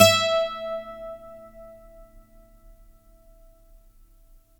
GTR 12STR 0E.wav